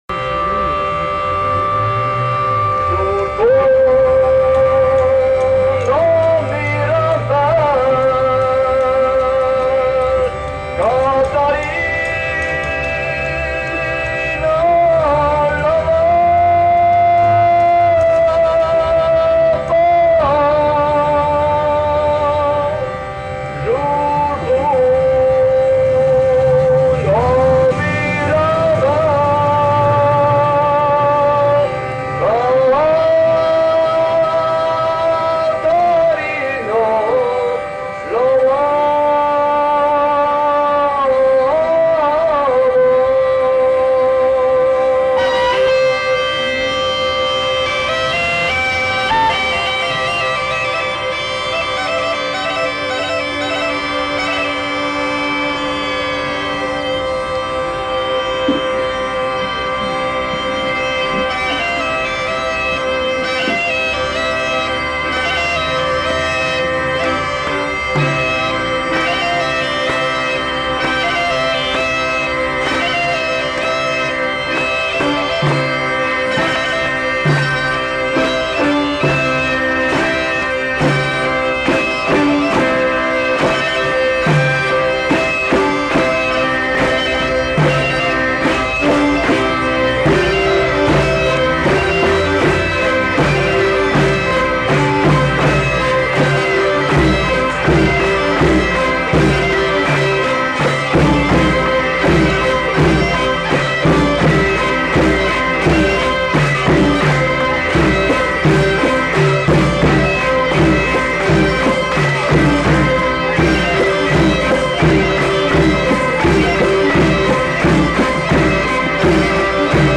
Aire culturelle : Pays d'Oc
Genre : chanson-musique
Type de voix : voix d'homme
Production du son : chanté
Instrument de musique : boha ; vielle à roue ; percussions
Suivi de deux autres airs.
• [enquêtes sonores] Veillée à Pinerolo en Val Varaita